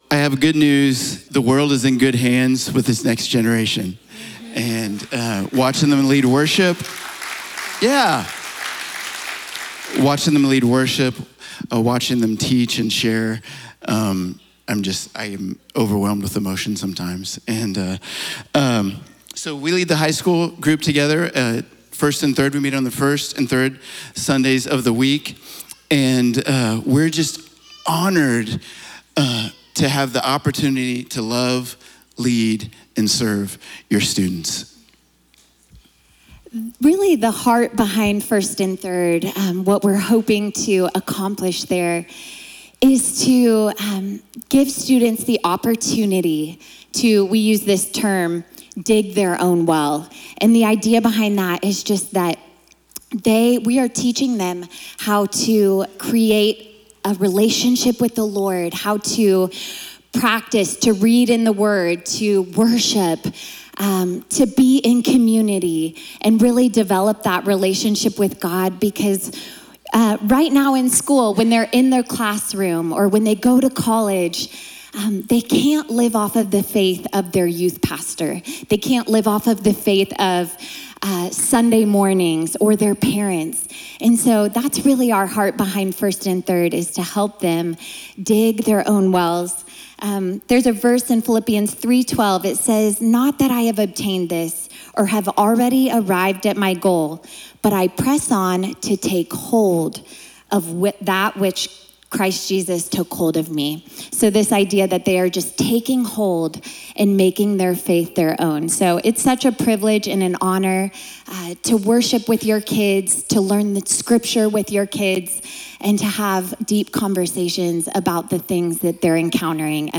Next Gen Sunday Service